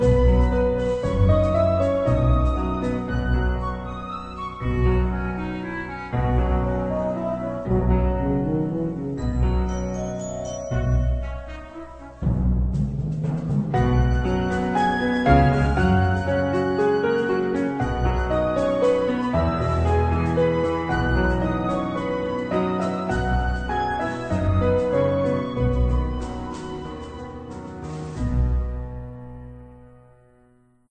oboe.mp3